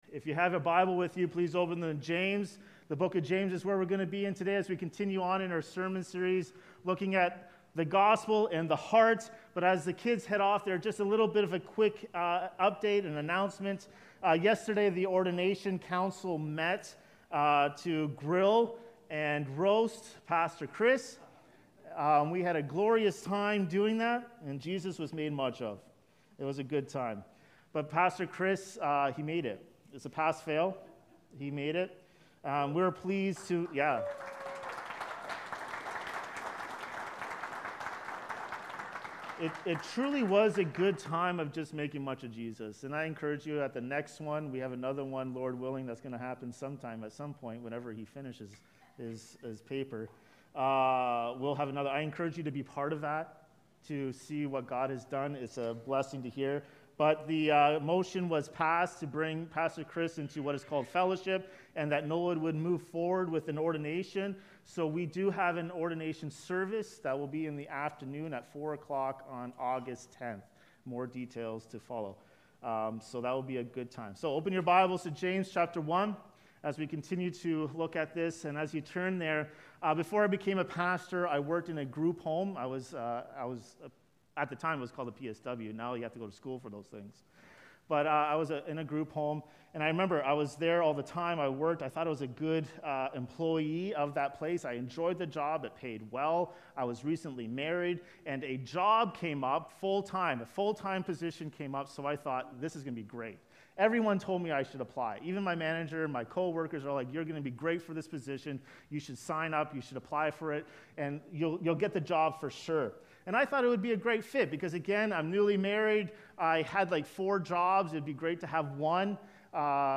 Discover how the gospel transforms our angry hearts in this sermon from James 1:19–21.